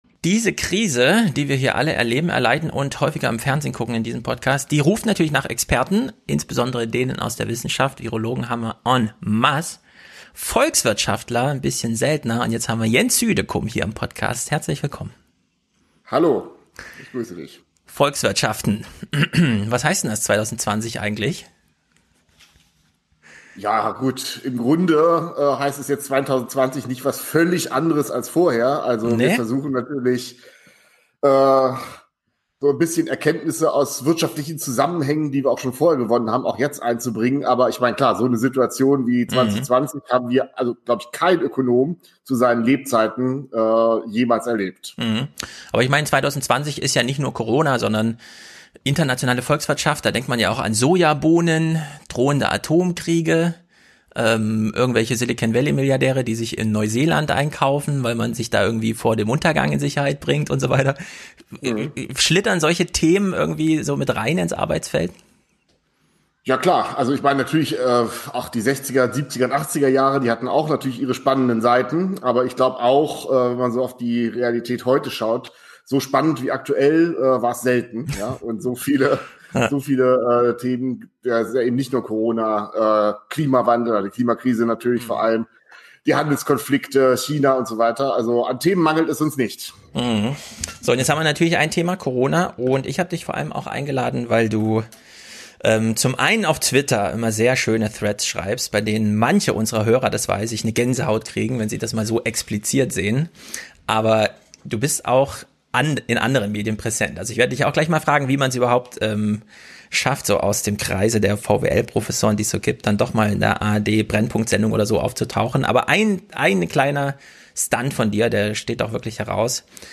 Dieses Gespräch